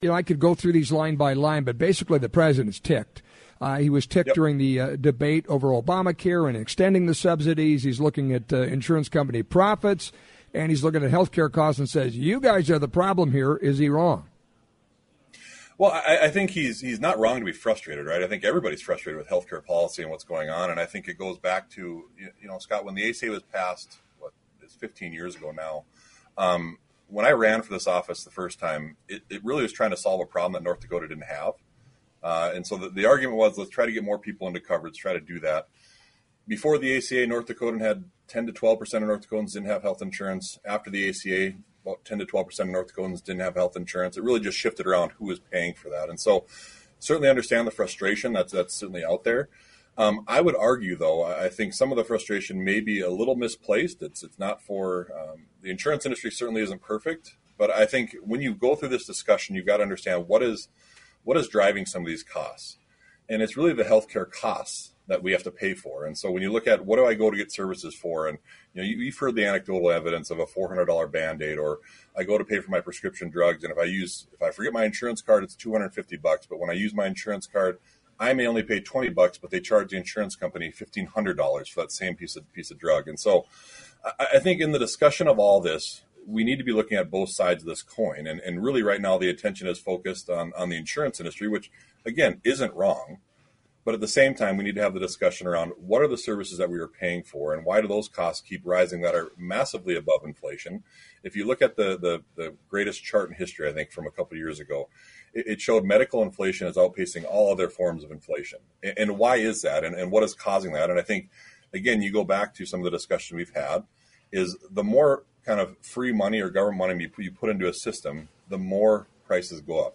North Dakota Insurance Commissioner Jon Godfread’s discussion